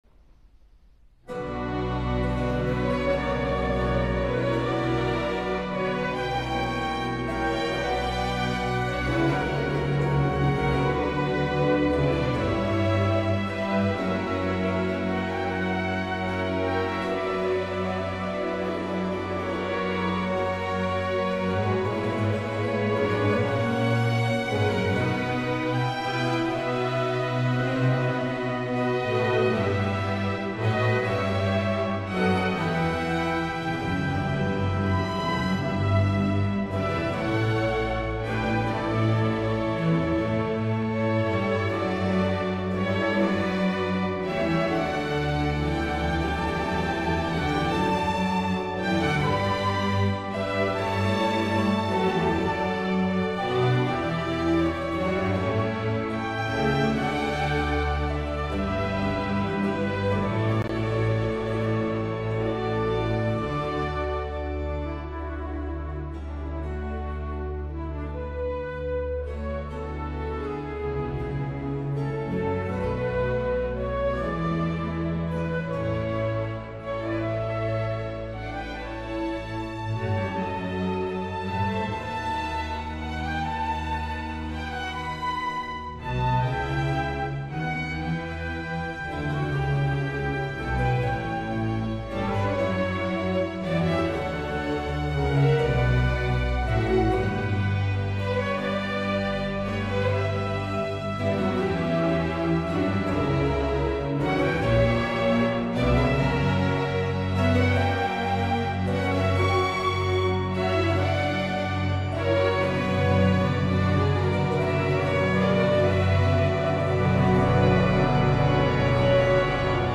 piano vertical